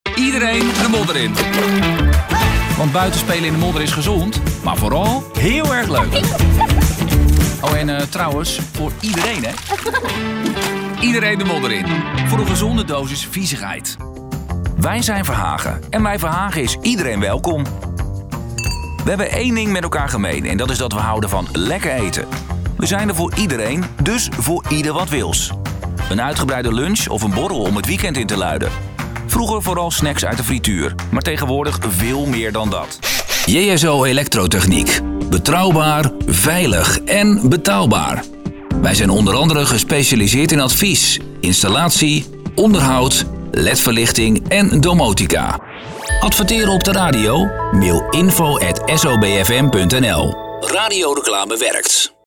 Natuurlijk, Opvallend, Toegankelijk, Vertrouwd, Vriendelijk
Commercieel